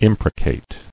[im·pre·cate]